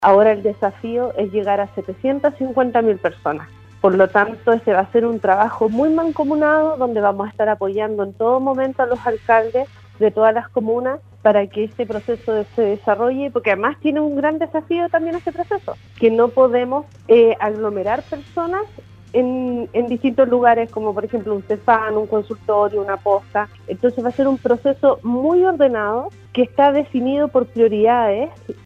En conversación con radio Sago la Gobernadora de la provincia de Llanquihue, Leticia Oyarce, se refirió a la llegada de las vacunas contra el Covid, que en la provincia alcanza a las 44 mil 400 dosis.